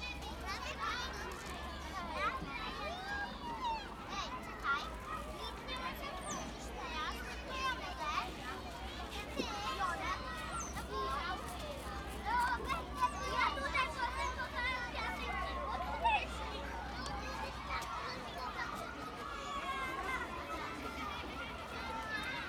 SFX_vocesBackground.wav